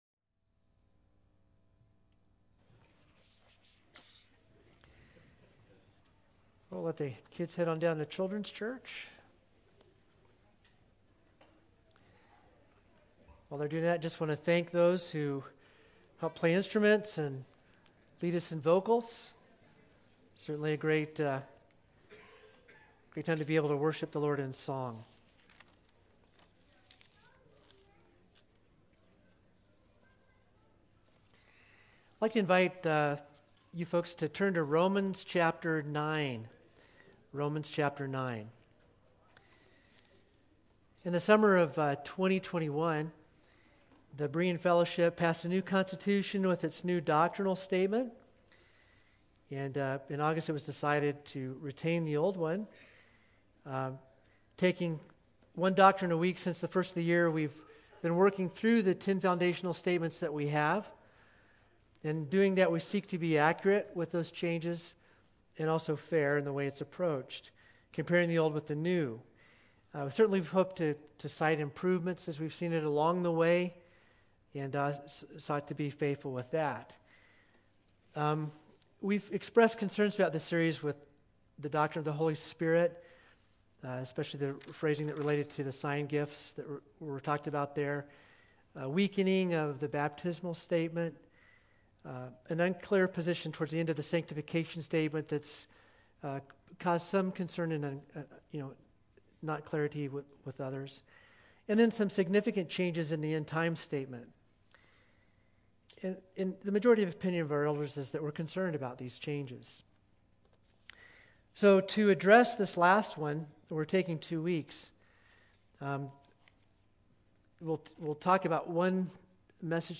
3_6-22-Sermon.mp3